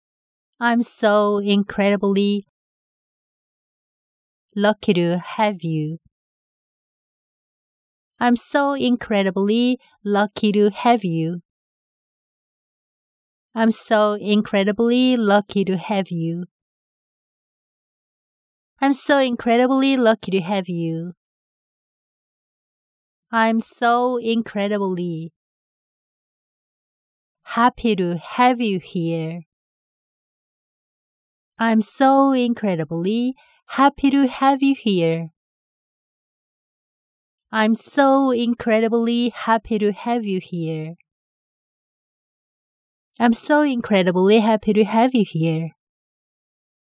중요한 단어만 길게 발음하고 나머지는 약하고 빠르게 발음합니다.
아임 쏘우 인크뤠러블리
러억키루
해뷰
해에피루
해에뷰 히어